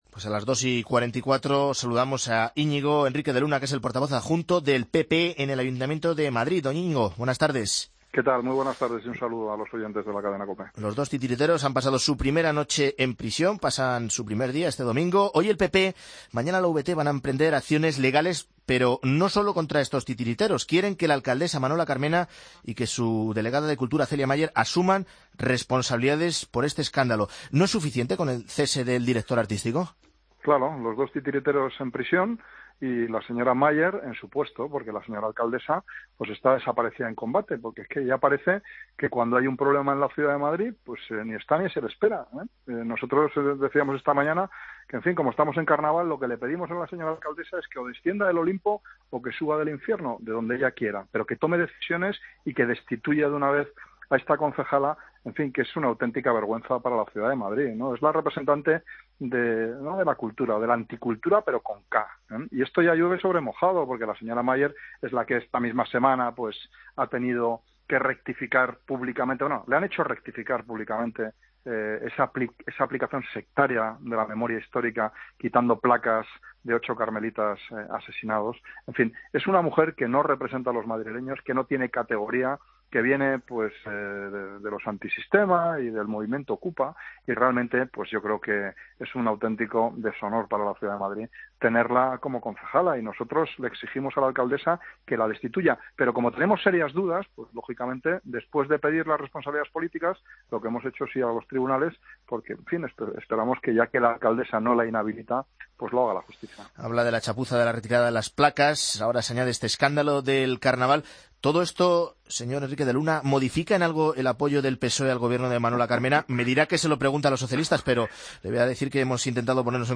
Escucha la entrevista al portavoz adjunto del PP en el Ayuntamiento de Madrid, Íñigo Henríquez de Luna, en Mediodía Cope.